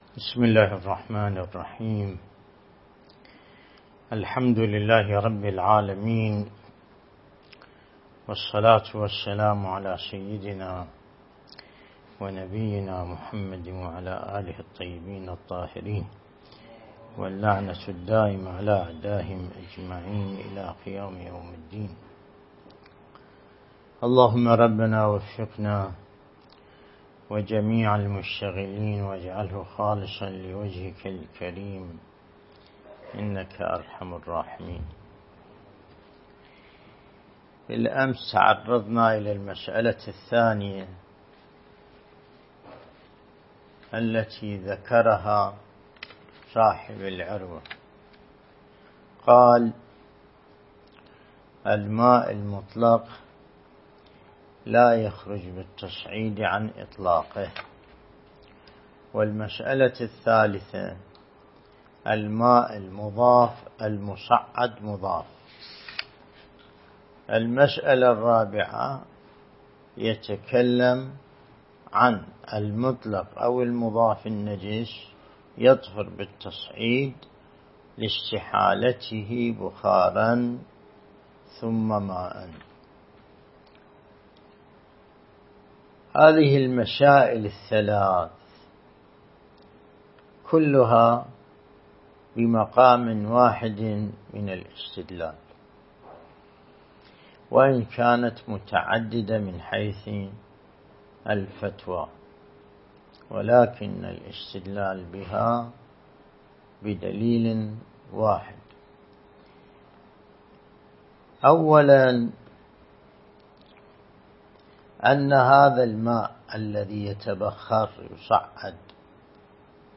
الدرس الاستدلالي